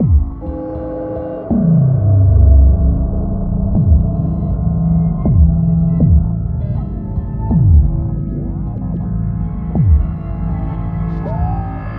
Эффект "Город роботов"
Тут вы можете прослушать онлайн и скачать бесплатно аудио запись из категории «Фантастика, Sci-fi».